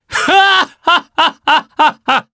Roman-Vox_Happy3_jp.wav